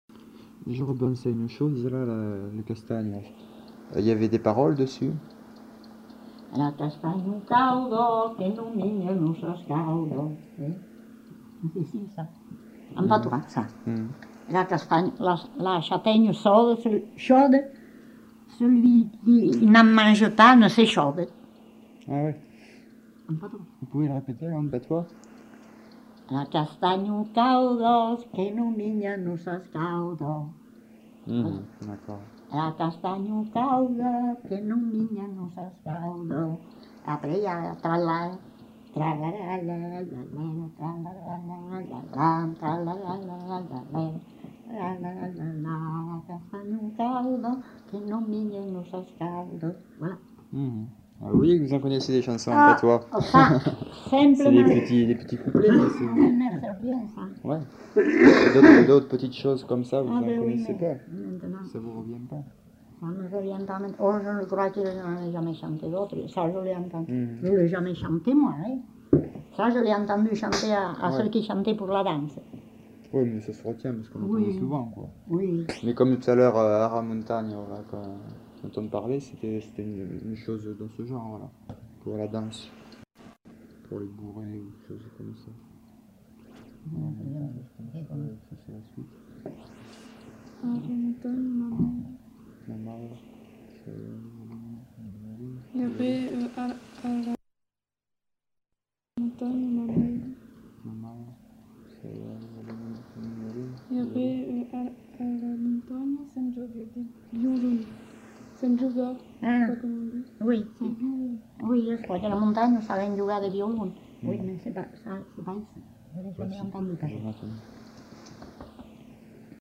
Aire culturelle : Couserans
Lieu : Pause-de-Saut (lieu-dit)
Genre : chant
Effectif : 1
Type de voix : voix de femme
Production du son : chanté
Danse : castanha